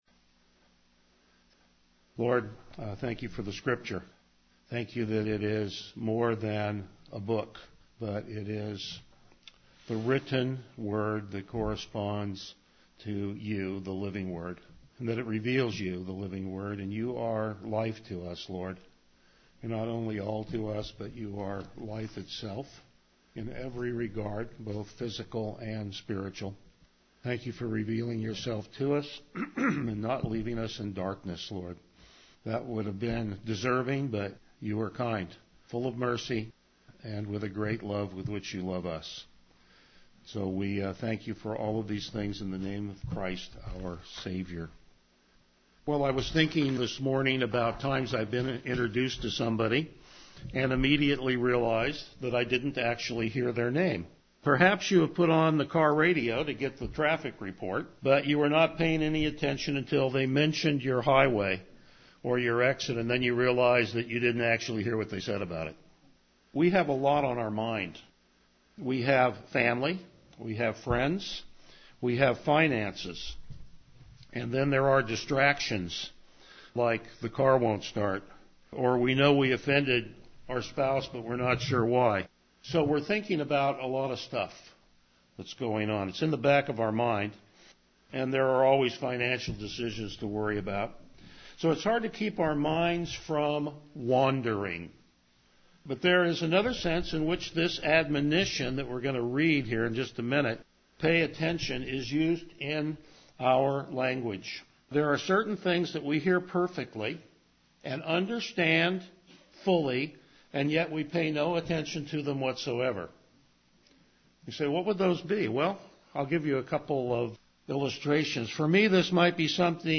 Passage: Hebrews 2:1-4 Service Type: Morning Worship Topics: Verse By Verse Exposition